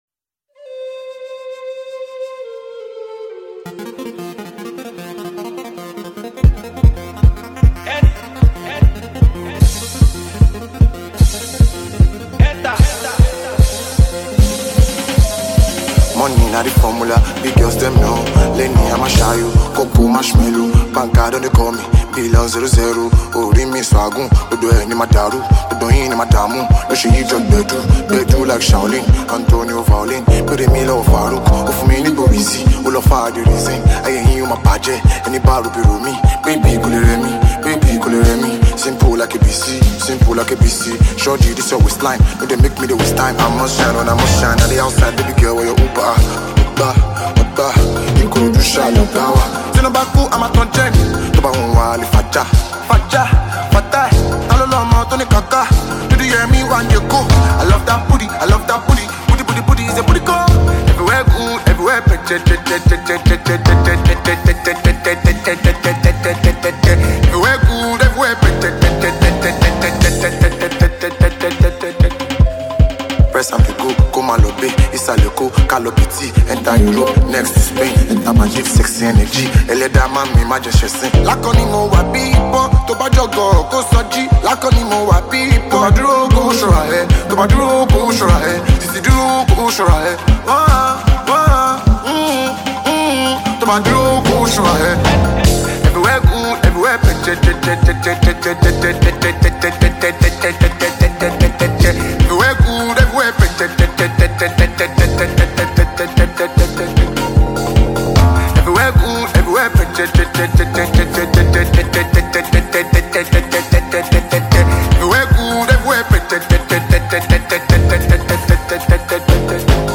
a Nigerian musician and songwriter